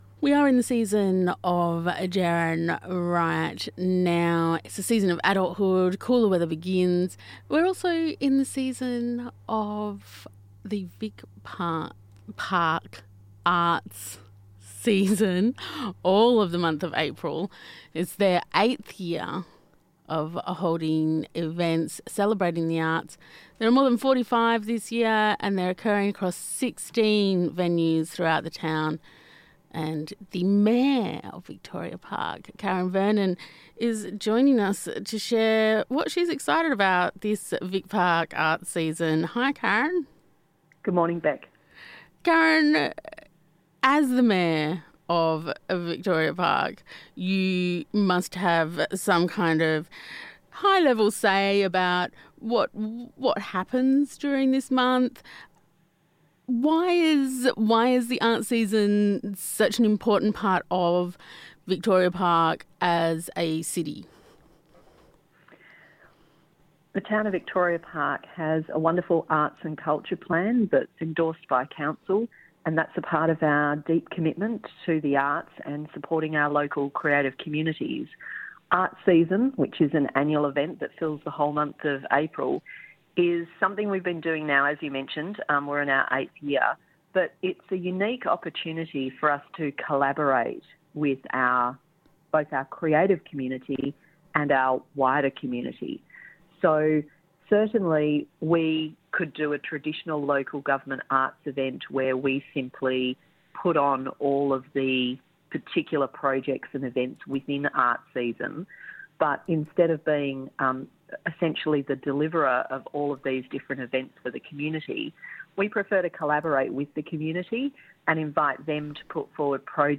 Vic Park Arts Season with Mayor, Karen Vernon